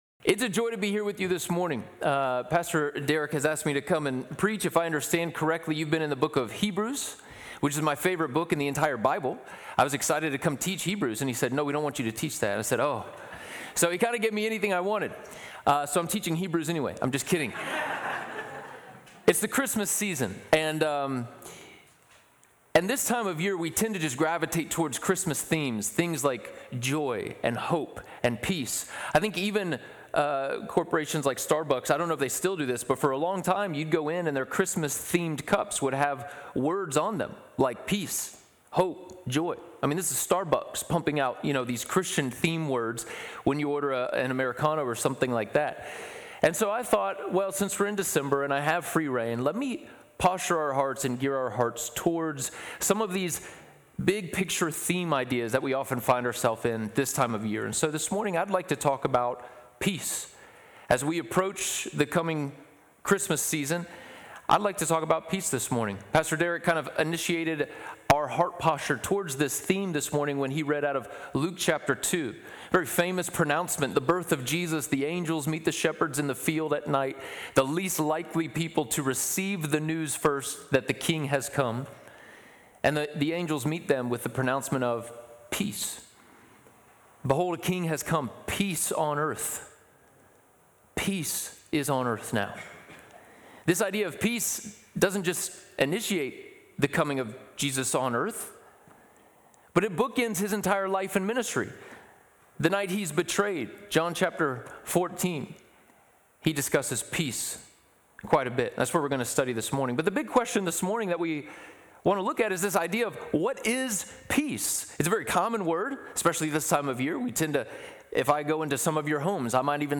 The sermon explores the concept of peace, particularly during the Christmas season, by examining biblical teachings and the role of the Holy Spirit. It highlights that peace is not merely the absence of difficult circumstances but the presence of the Spirit of God within us.